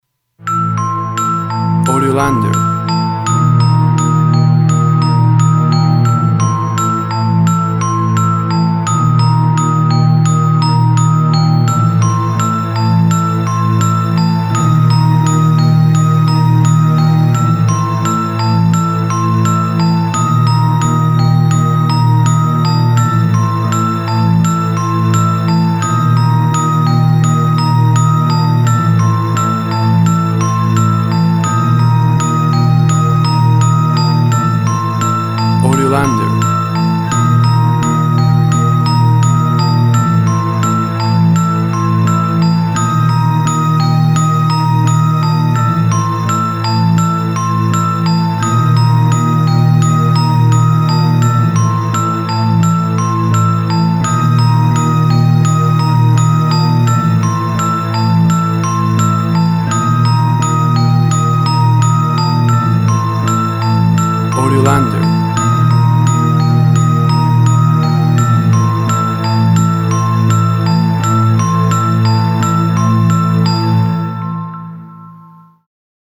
Music for horror scene.
Tempo (BPM) 100